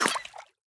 Minecraft Version Minecraft Version snapshot Latest Release | Latest Snapshot snapshot / assets / minecraft / sounds / mob / axolotl / attack3.ogg Compare With Compare With Latest Release | Latest Snapshot
attack3.ogg